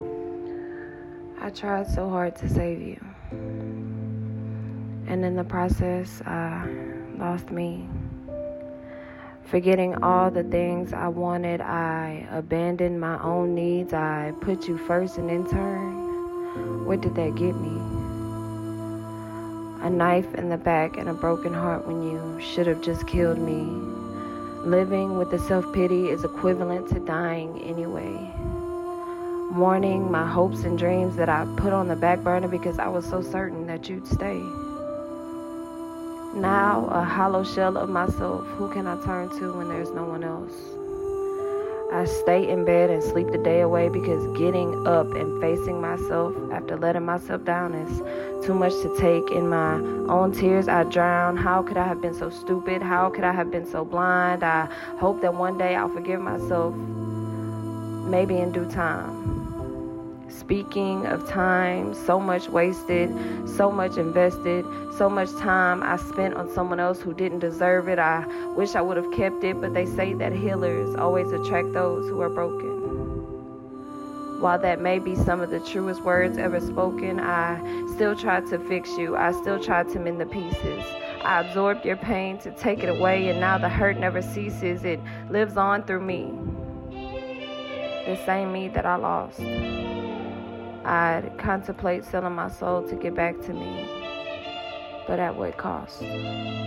Poetry
Your voice and your words were just beautiful.